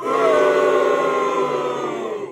Boo.wav